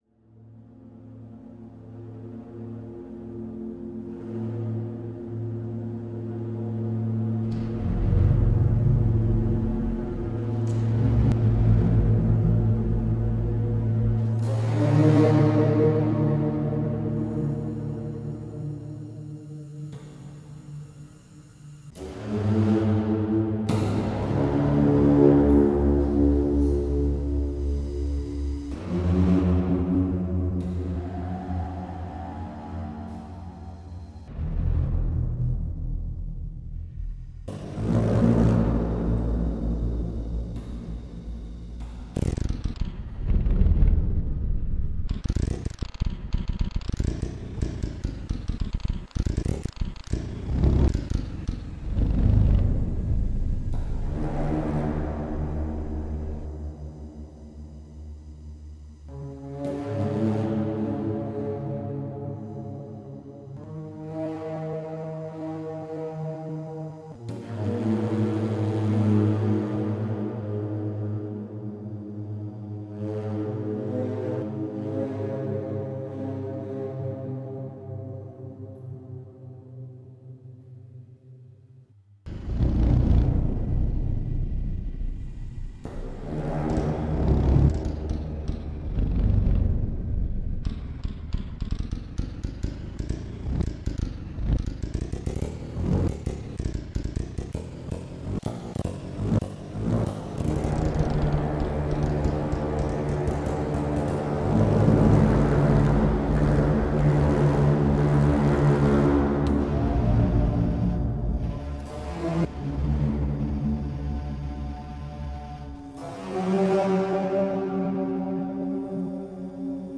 Electroacoustic
Fixed sounds